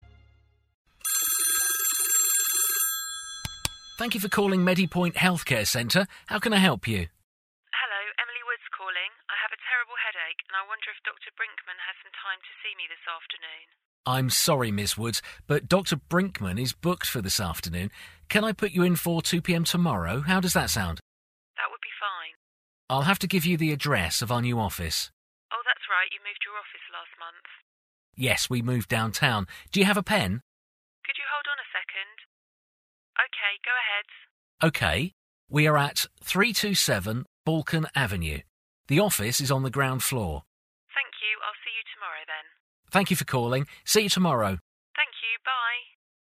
Telefonos angol – Időpontfoglalás